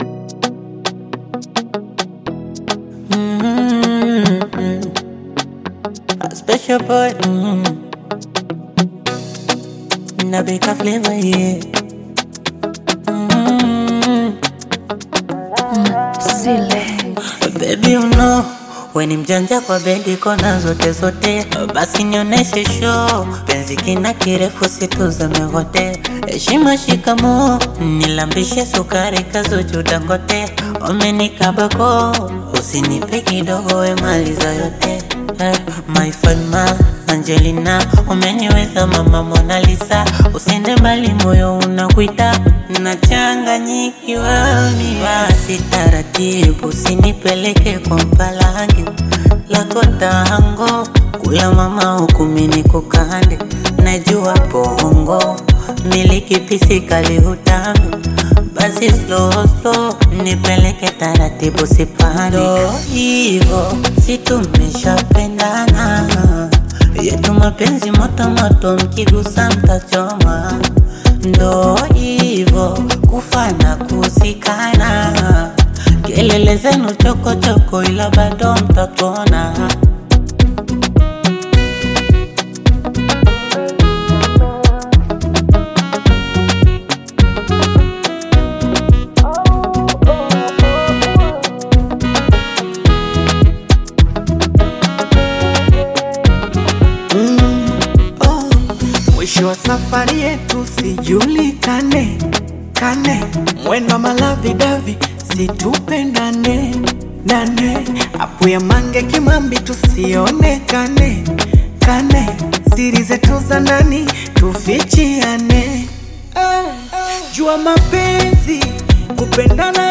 Gospel music track
Tanzanian Bongo Flava